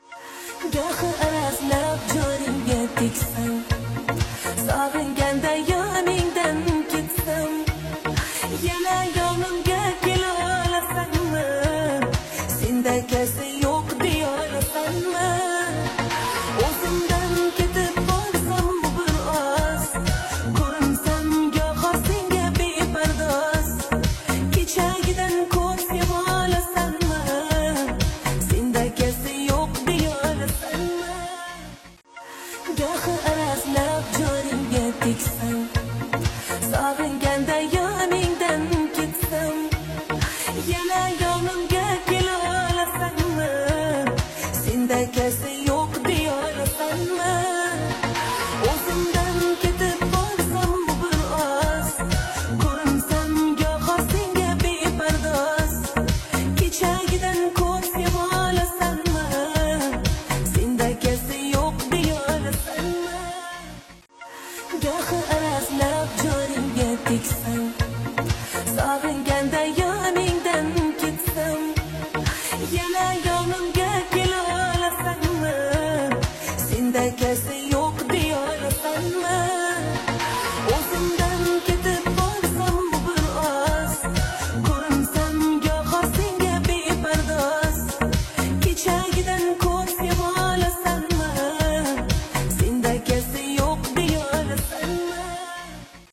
Кавказская музыка